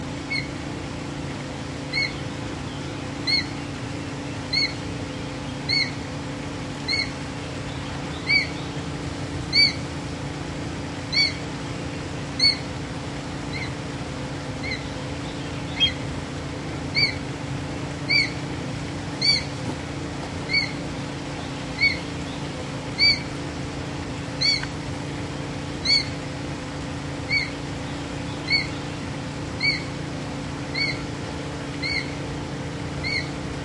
白头鹰
标签： 白头鹰
声道立体声